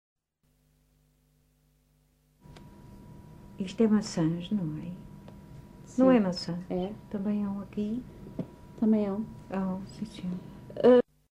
Text view Fajãzinha, excerto 52 Localidade Fajãzinha (Lajes das Flores, Horta) Assunto As árvores de fruto e os frutos Informante(s